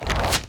Rock Meteor Throw 1.ogg